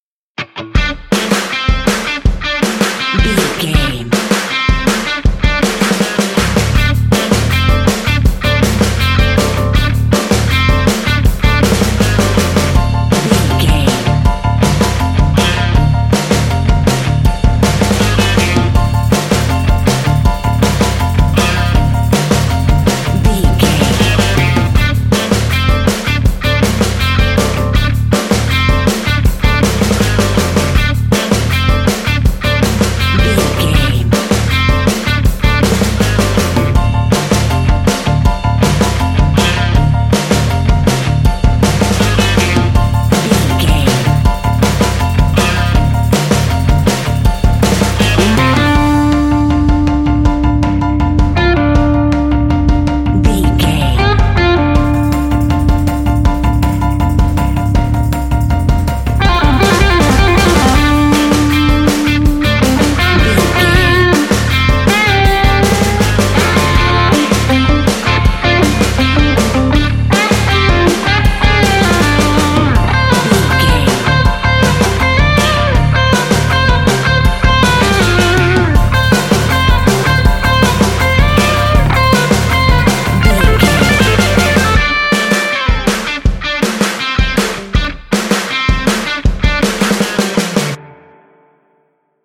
Aeolian/Minor
Fast
groovy
energetic
electric guitar
bass guitar
synthesiser
electric piano